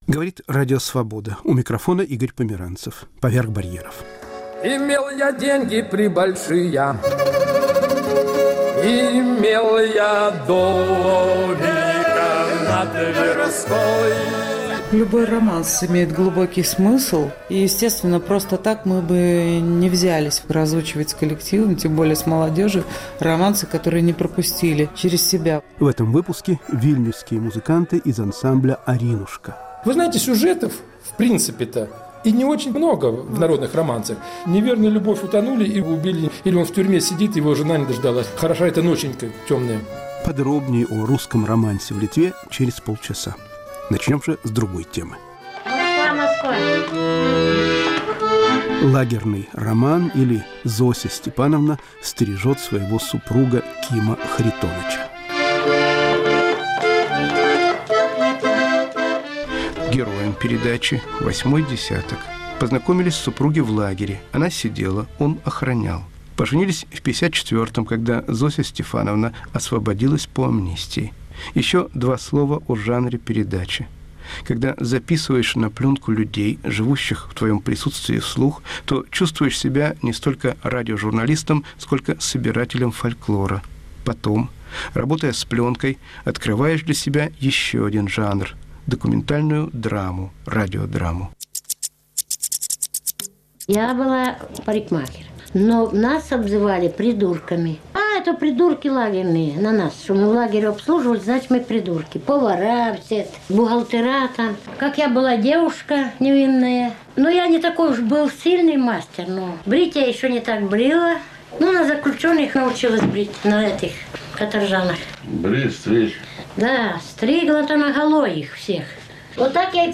Русско-украинский диалог: поэты Елена Фанайлова и Сергей Жадан